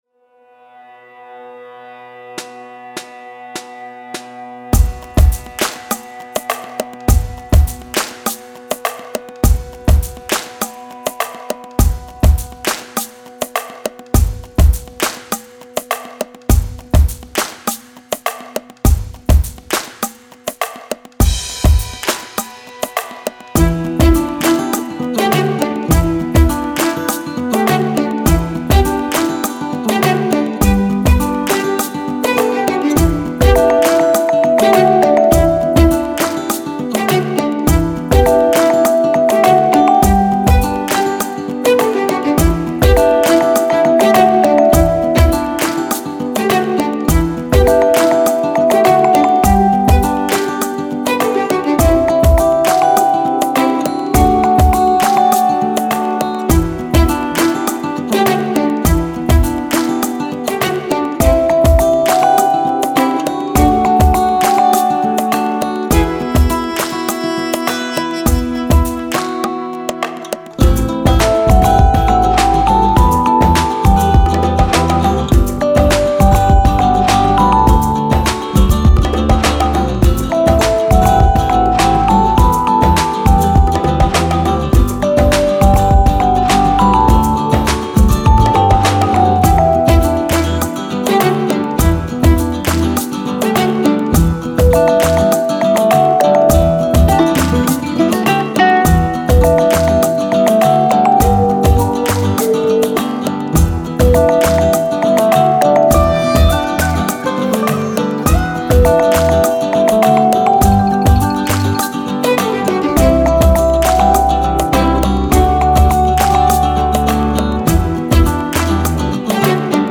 Ici et ailleurs: version instrumentale